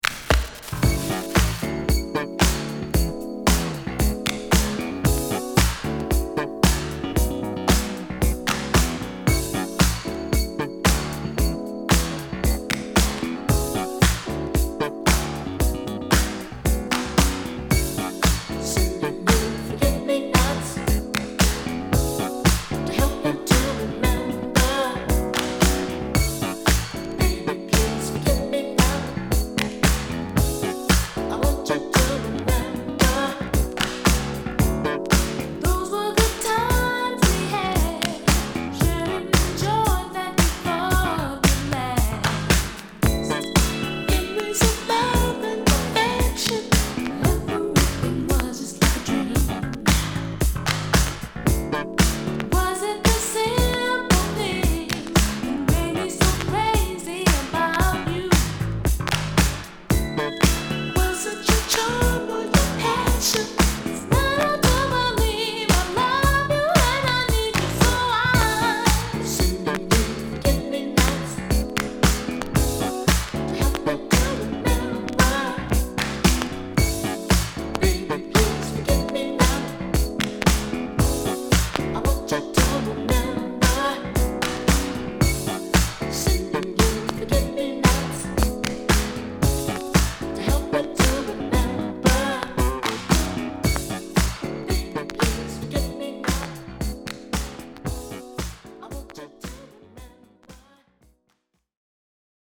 Format: 7 Inch
※見た目は綺麗ですが頭の部分で気になるノイズが入ります。
※この盤からの録音ですので「試聴ファイル」にてご確認下さい。